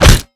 FleshWeaponHit1.wav